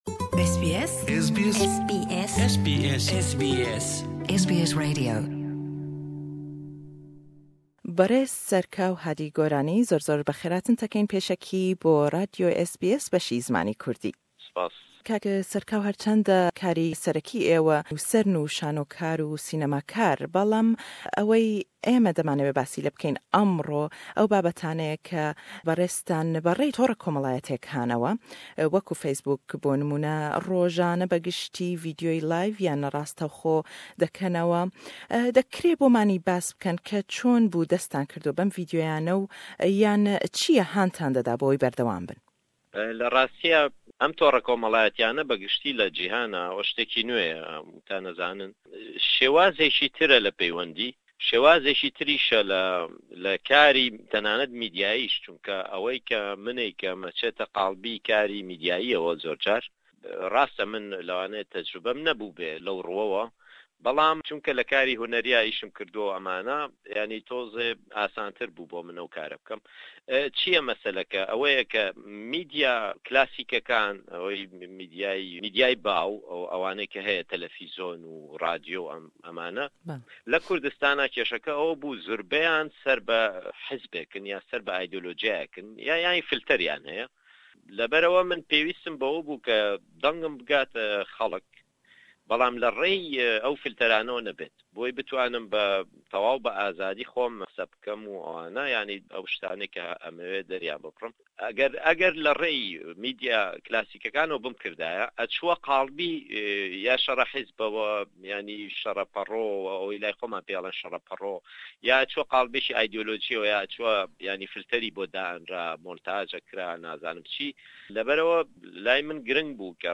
fire babtî dîke. le em hevpeyvîne da pisîyarî lêdekeyn sebaret be ew hokar û handeraney ke hetakû êsta berdewam bin le billaw kirdinewey ew vidyo yane.